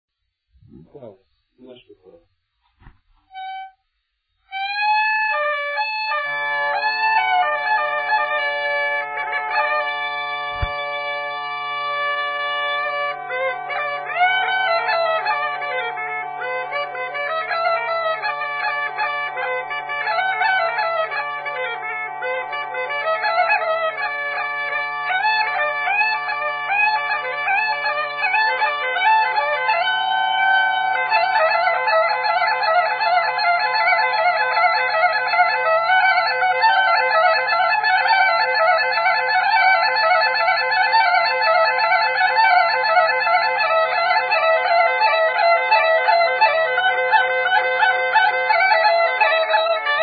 музикална класификация Инструментал
размер Две четвърти
фактура Двугласна
начин на изпълнение Солово изпълнение на гайда
битова функция На хоро
фолклорна област Югоизточна България (Източна Тракия с Подбалкана и Средна гора)
място на записа Покрован
начин на записване Магнетофонна лента